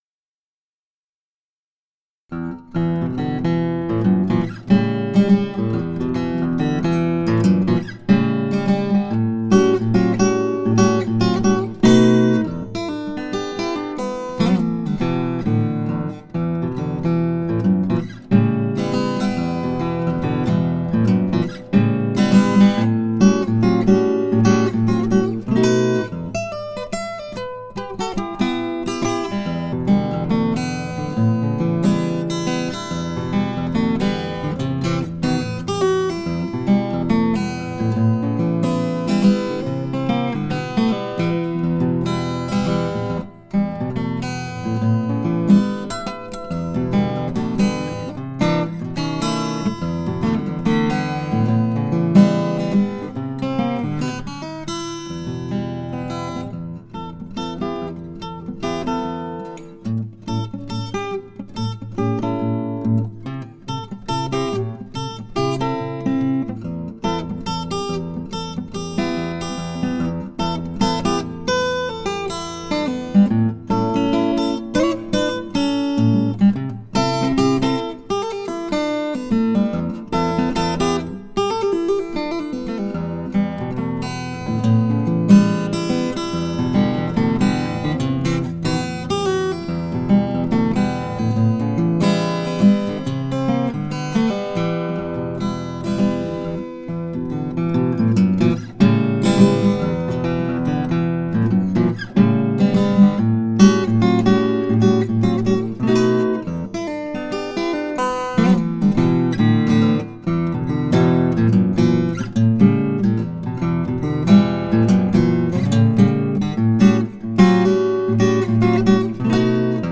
[Демо] Collings C10 Deluxe тоны
Мой комп из нескольких лет назад на мощном Collings C10 Deluxe.
Мне это очень нравится, хорошая запись и очень хороший стиль.
ThreeBaseBlues2008.mp3